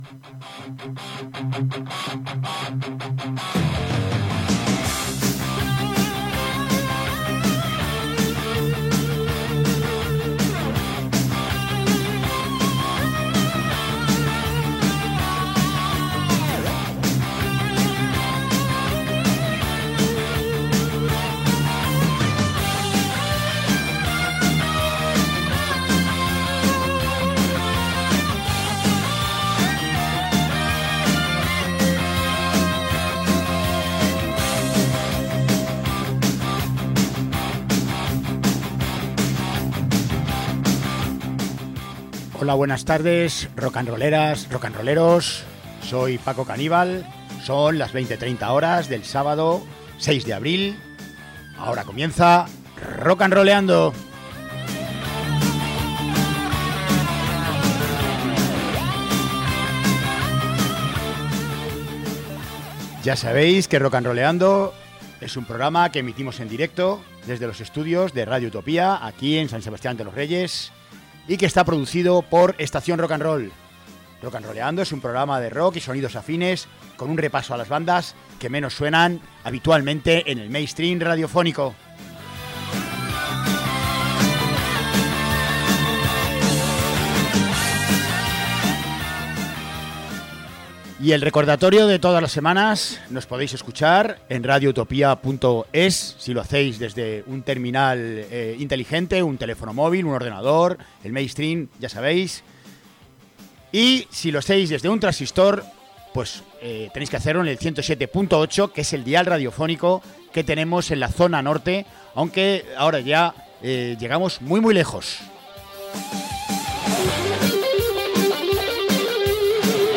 setlist especial dedicada al doom, gothic e industrial
Ritmos pulsantes, sintetizadores envolventes